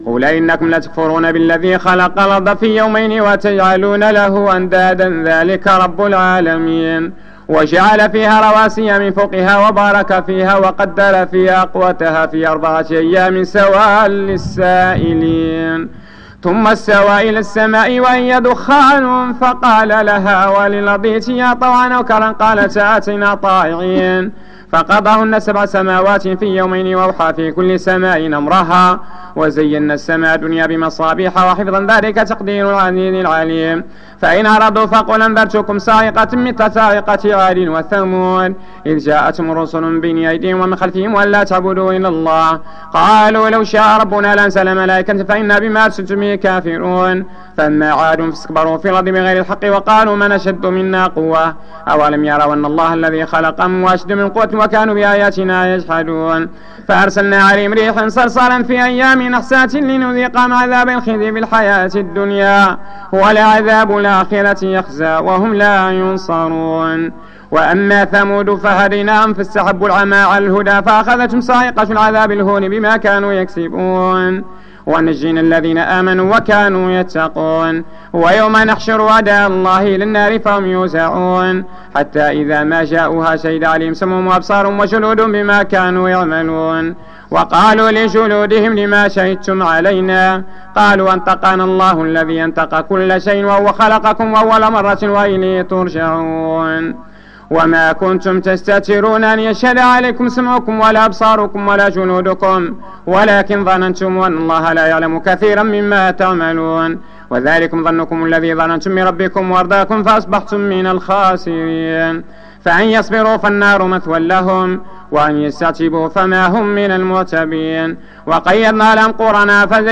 صلاة التراويح رمضان 1431/2010 بمسجد ابي بكر الصديق ف الزوى